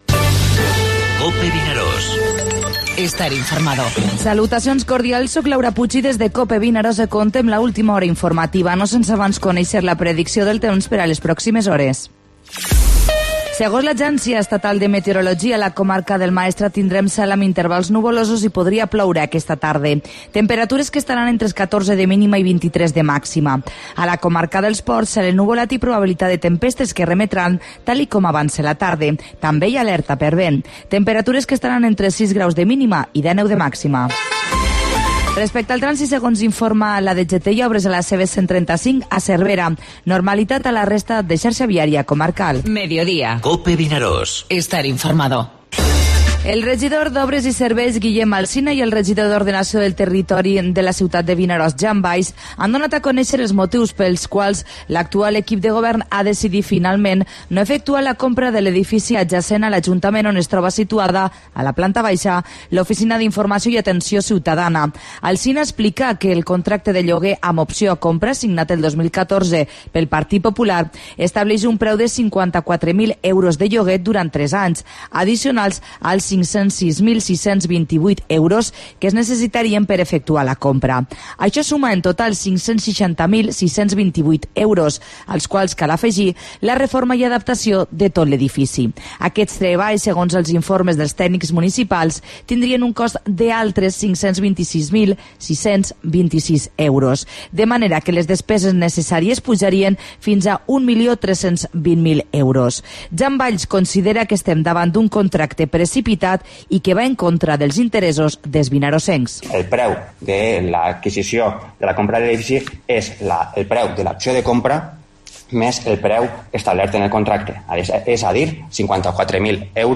Informativo Mediodía COPE al Maestrat (18/5/17)